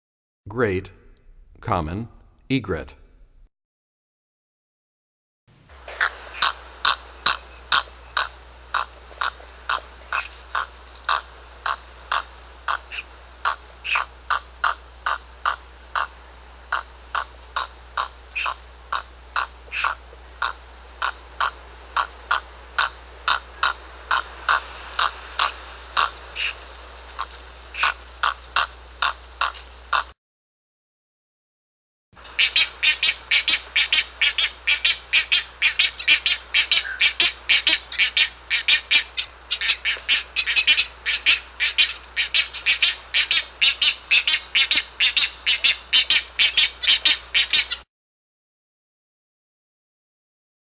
41 Great Common Egret.mp3